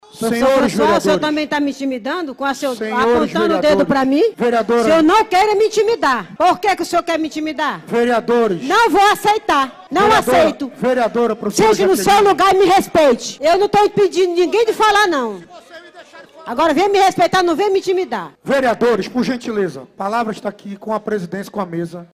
Os vereadores da Câmara Municipal de Manaus batem boca e trocam farpas durante sessão plenária nesta terça-feira, 27.